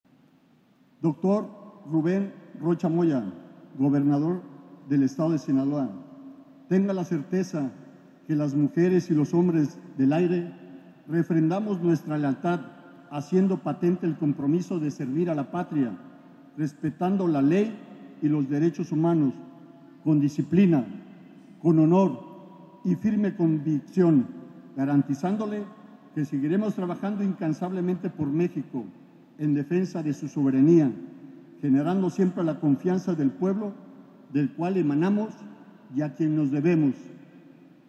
Culiacán, Sinaloa, a 10 de febrero de 2026.- El gobernador Rubén Rocha Moya asistió a la ceremonia para conmemorar el CXI Aniversario de la Fuerza Aérea Mexicana, que tuvo lugar en el hangar de la Base Aérea Militar Número 10, donde su comandante, el general de Ala Piloto Aviador, Edgar Emanuel Campos Martínez, fue el anfitrión para los invitados especiales, contando también con la presencia del comandante de la Tercera Región Militar, general de División, Héctor Ávila Alcocer.
Durante su mensaje, el comandante de la BAM, Campos Martínez, precisó que el 10 de febrero de cada año es una fecha de gran relevancia para la Fuerza Aérea Mexicana, que refrenda una vez más su compromiso y lealtad hacia el pueblo de México.